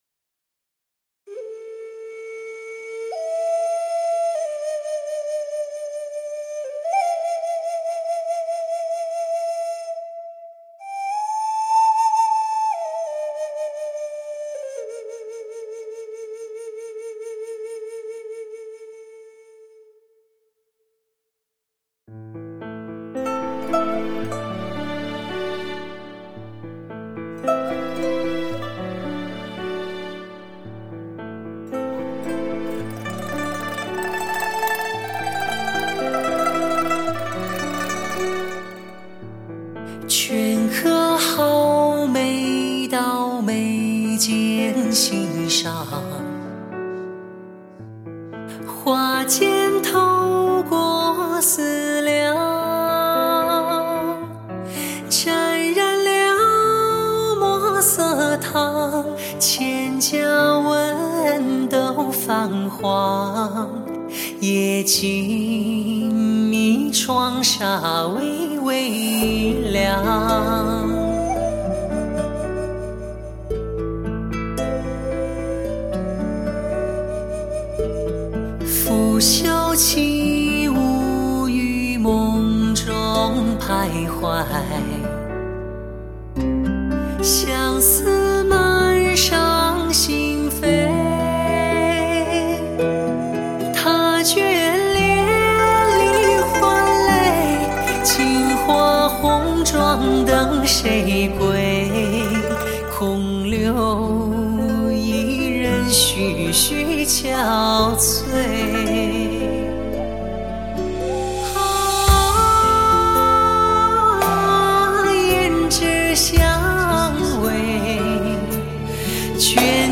新一代无损音频技术与黑胶母盘直刻技术的完美结合
声色俱佳 挑战完美音质的顶级发烧大碟
在驾驭空间内实现全方位360°听觉特效